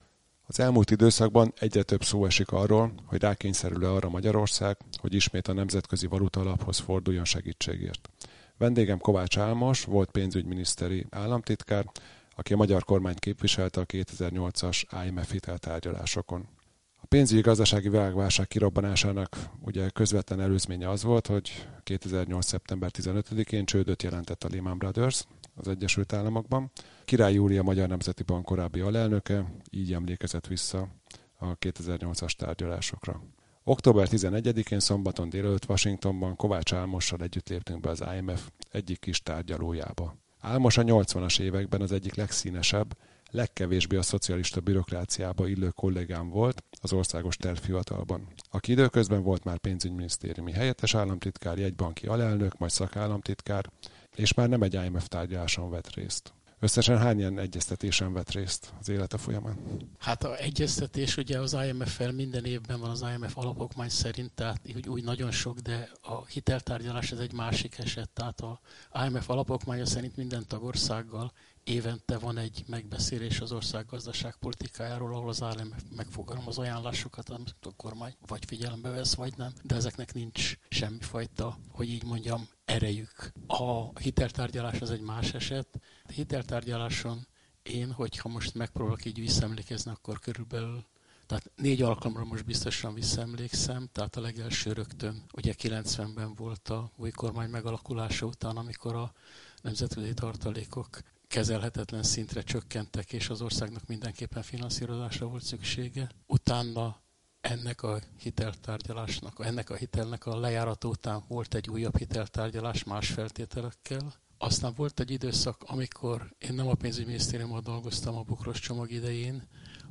Egy telefonhívással kezdődtek a tárgyalások a valutaalappal. Arról beszélgettünk Kovács Álmossal, a Pénzügyminisztérium korábbi államtitkárával, hogy mi történt akkor, és milyen tárgyalófél az IMF. Ez a Szabad Európa podcastjának szerkesztett változata.